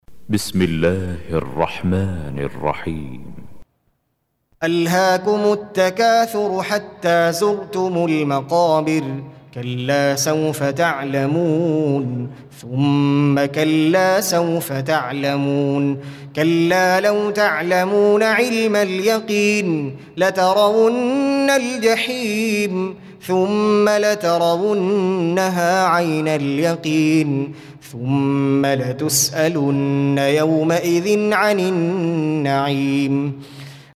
Surah Repeating تكرار السورة Download Surah حمّل السورة Reciting Murattalah Audio for 102. Surah At-Tak�thur سورة التكاثر N.B *Surah Includes Al-Basmalah Reciters Sequents تتابع التلاوات Reciters Repeats تكرار التلاوات